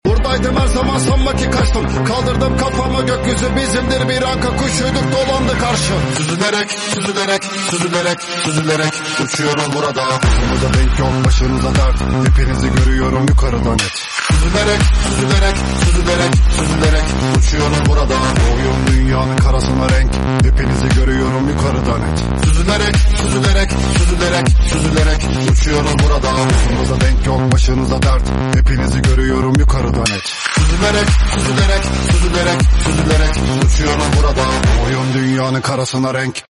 MT 25 top speed💨 sound effects free download